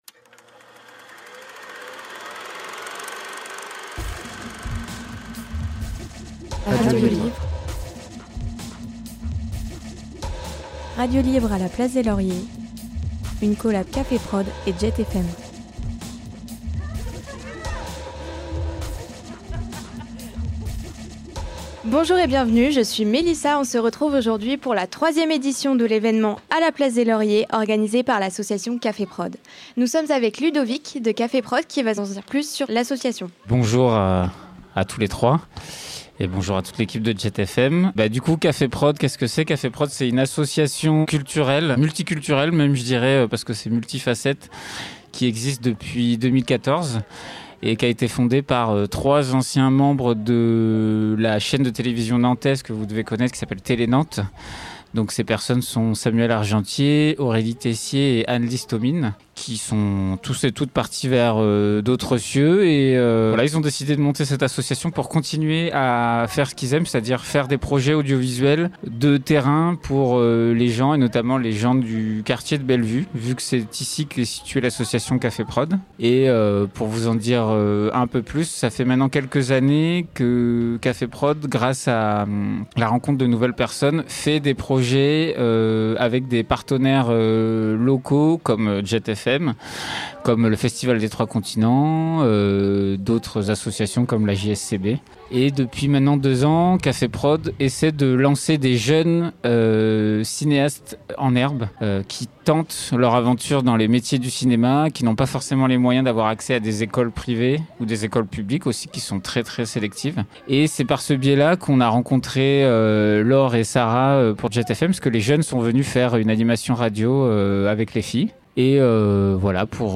En ce qui concerne la radio, ce sont les jeunes de Radio Libre avec Jet FM qui ont préparé et animé le plateau en extérieur, devant la Maison des Habitants et du Citoyen, square Michelle Pallas, anciennement identifié sous le nom de la place des Lauriers .
Découvrez donc le contenu de ces interviews, ainsi que l’ambiance sur le plateau, avec le podcast intégré au début de l’article.